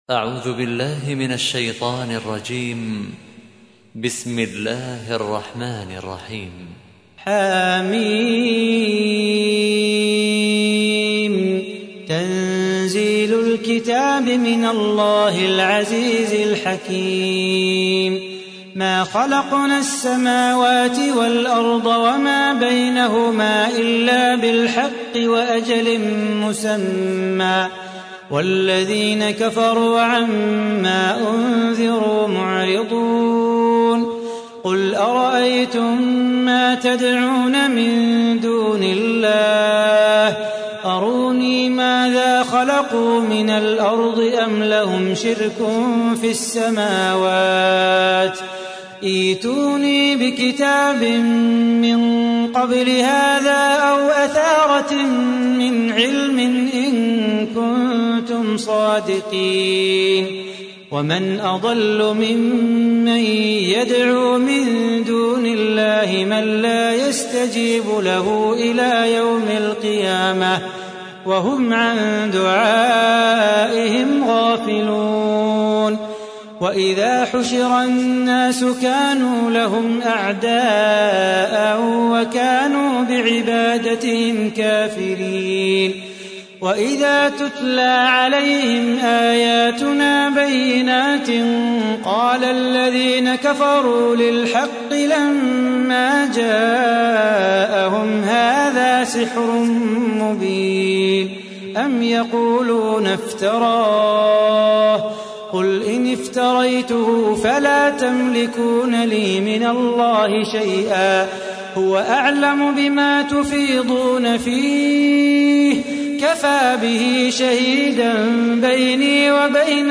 تحميل : 46. سورة الأحقاف / القارئ صلاح بو خاطر / القرآن الكريم / موقع يا حسين